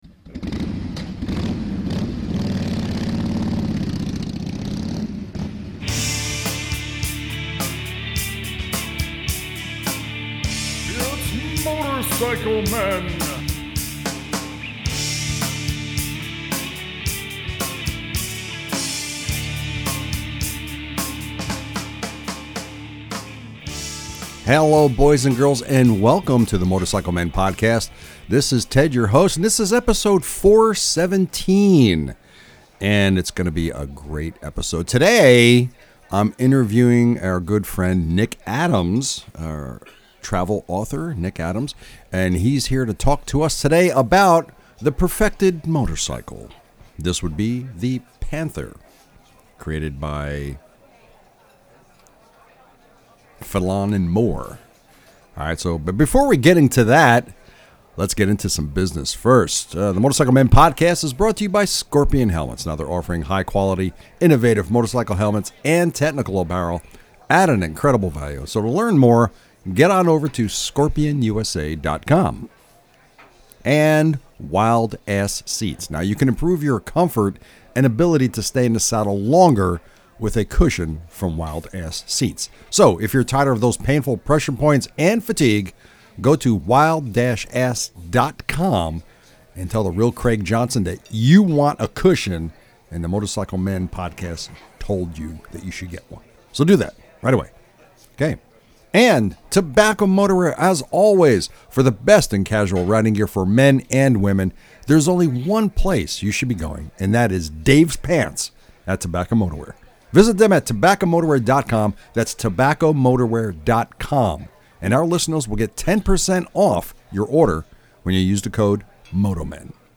In this episode I interview